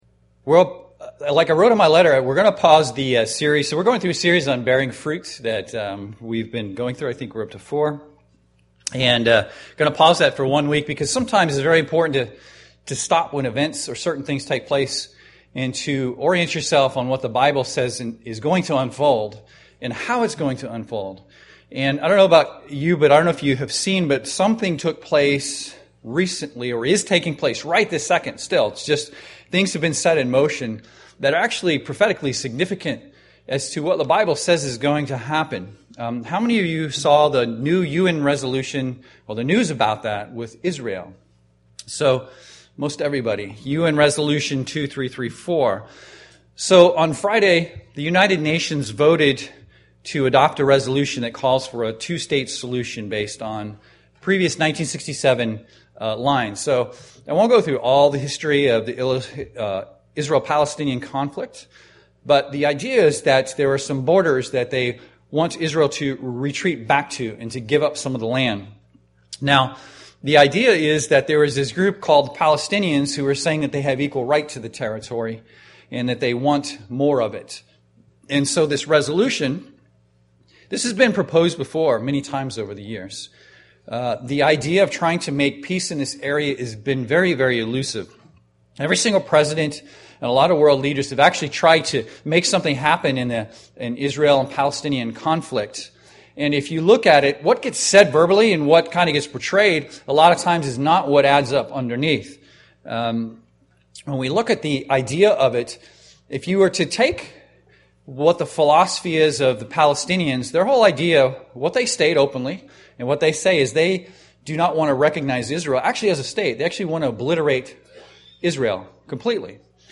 This sermon shows how this shift is actually a monumental event in time and as outlined in the bible.
Given in Seattle, WA